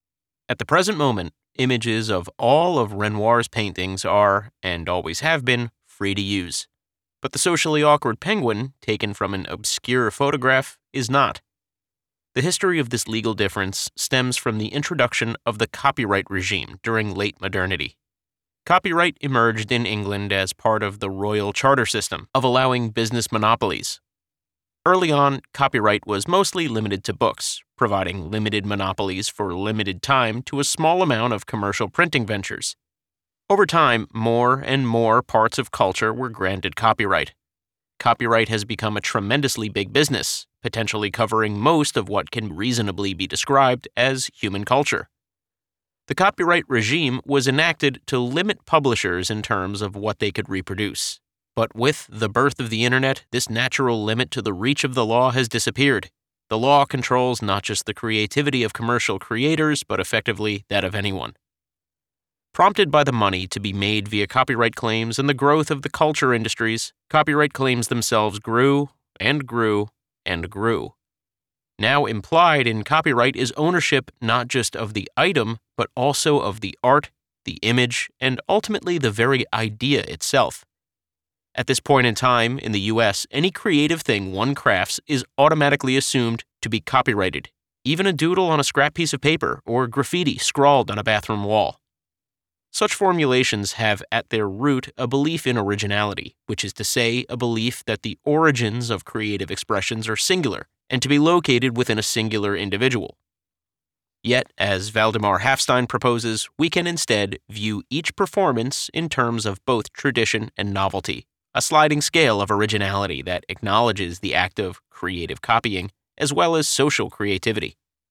Audiobook Demo (nonfiction)
American (Generic and Regional)
Middle Aged
Audiobook Demo.mp3